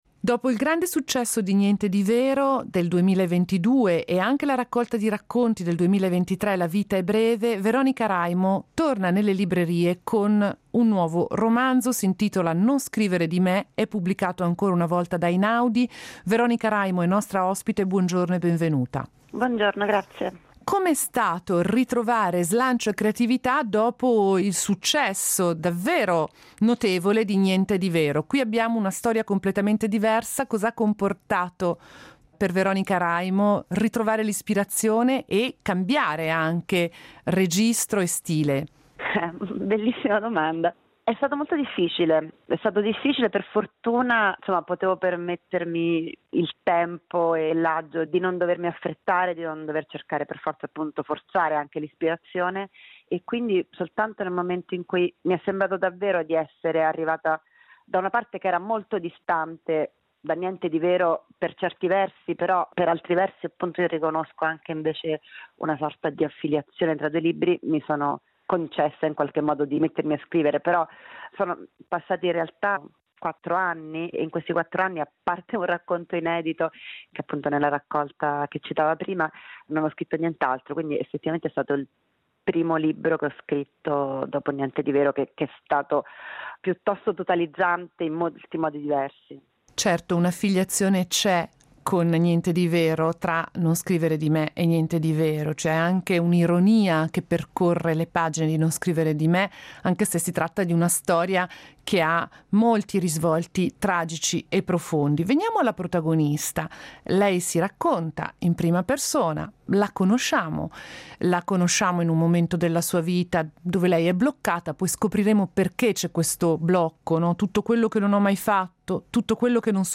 Intervista a Veronica Raimo